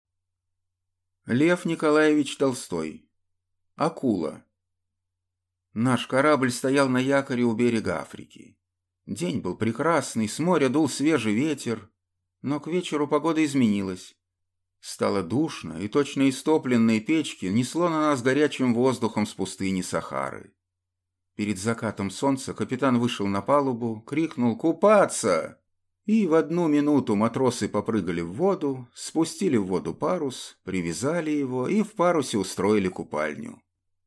Аудиокнига Акула | Библиотека аудиокниг